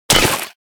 crack_wolf2.ogg